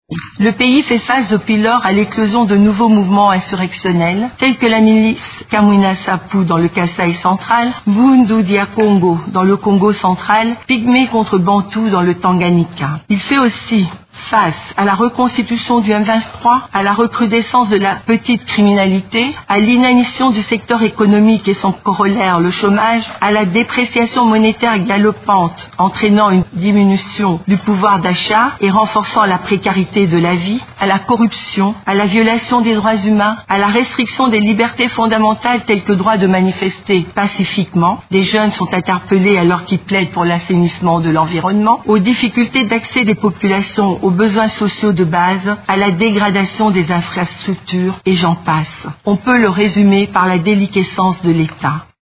Intervenant, mardi 21 mars, devant le Conseil de sécurité de l’ONU, elle a également déploré la crise occasionnée par la non-organisation des élections en 2016.